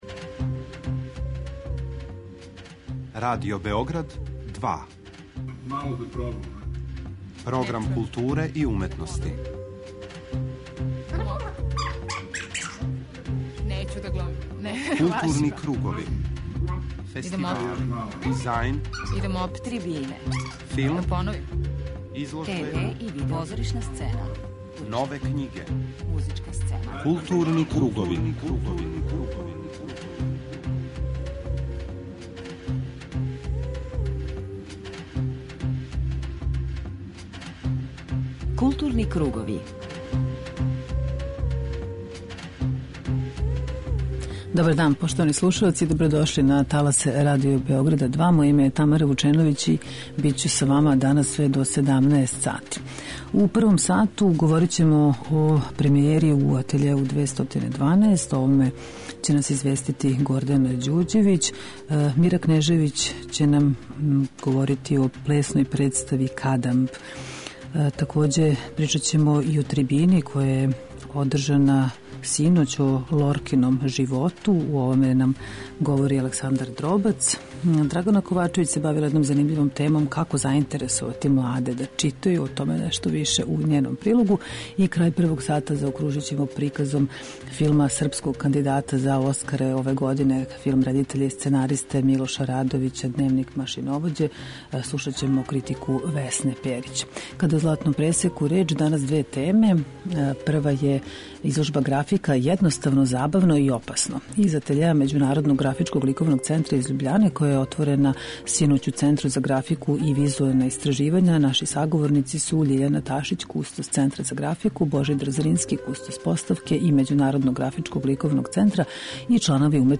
преузми : 38.33 MB Културни кругови Autor: Група аутора Централна културно-уметничка емисија Радио Београда 2.